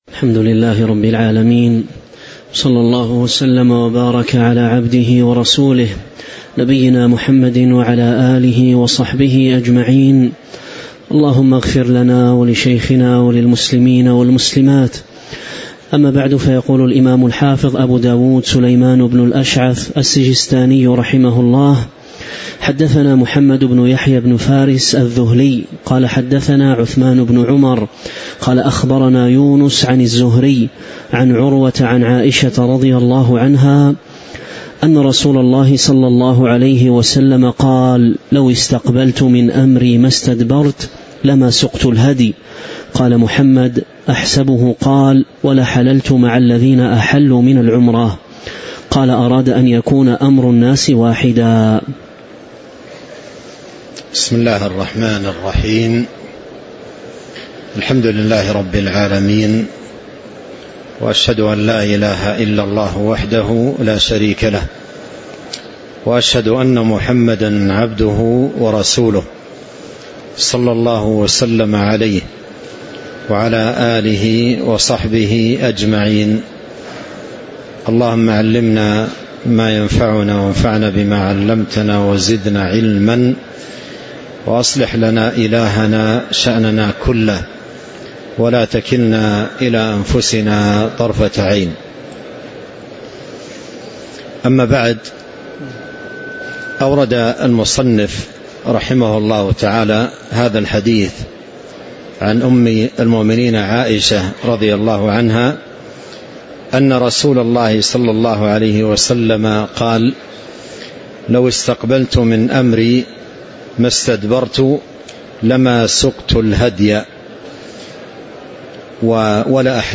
تاريخ النشر ٢٦ ذو القعدة ١٤٤٦ المكان: المسجد النبوي الشيخ